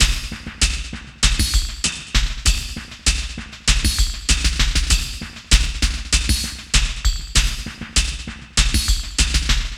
98WAGONLP4-L.wav